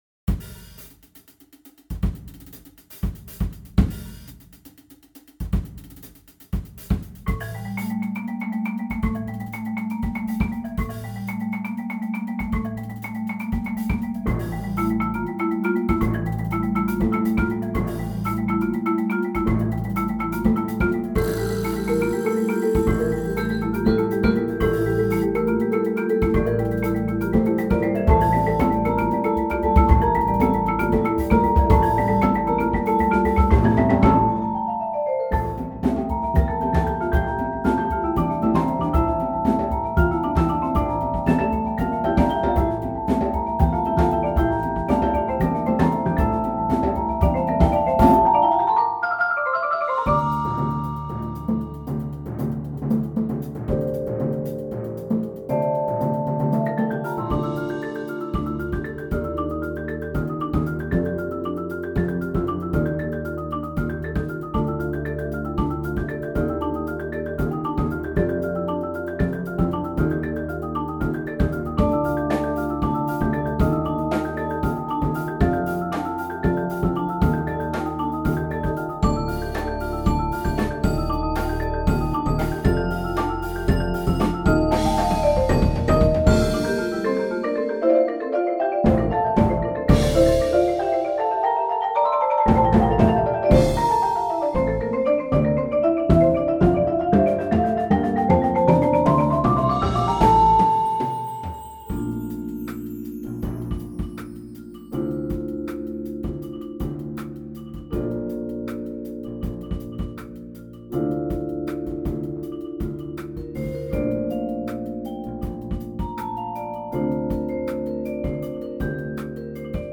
• Personnel: 10 or 11 players